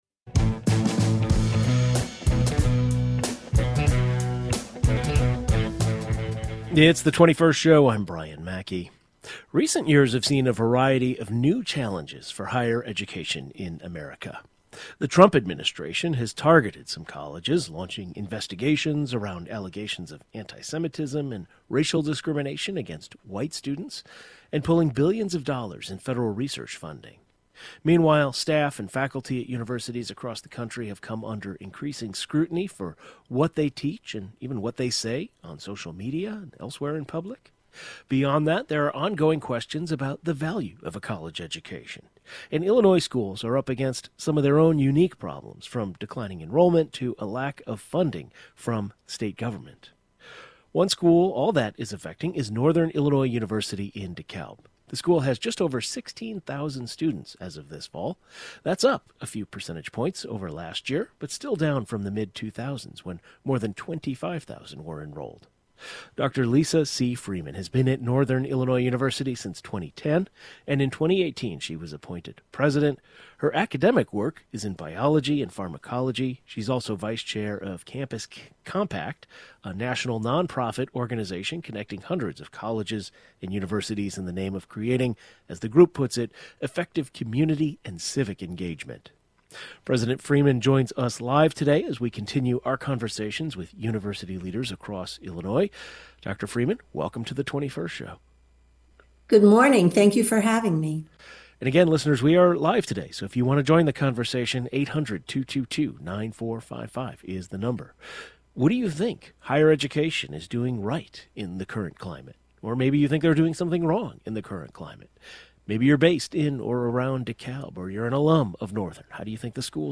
The president of Northern Illinois University discusses how her school is responding to challenges in higher education.